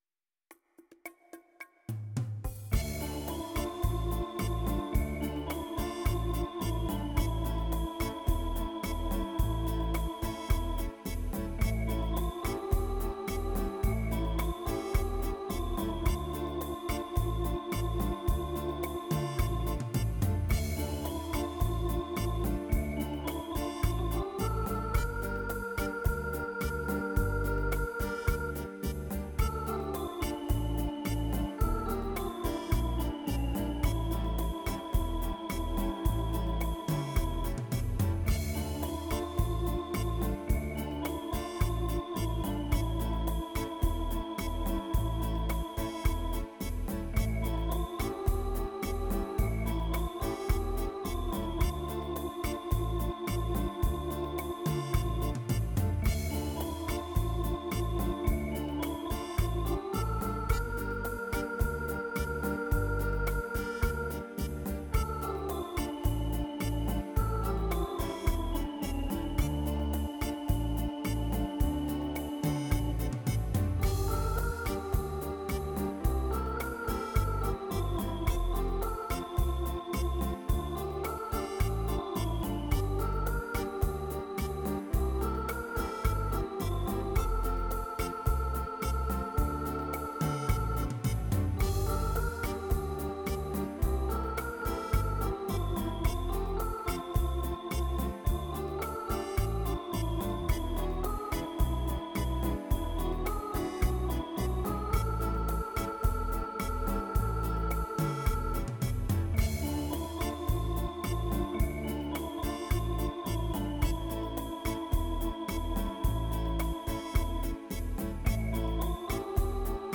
Rumba